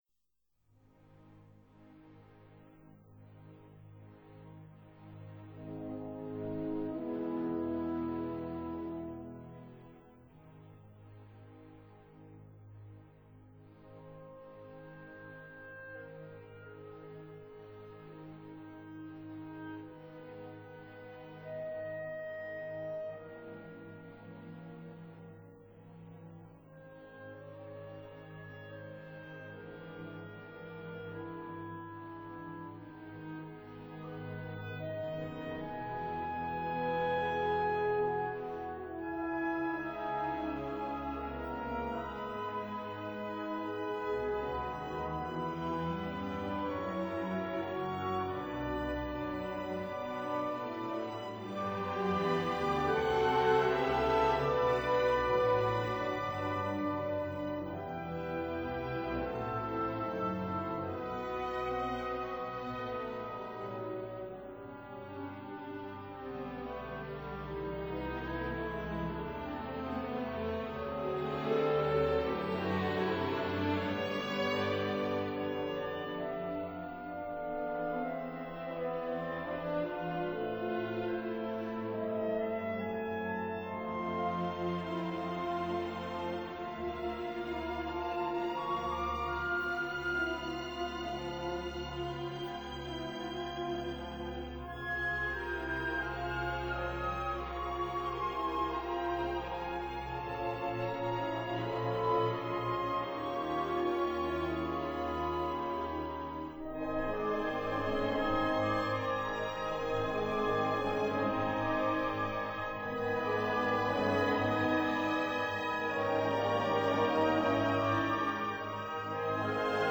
C minor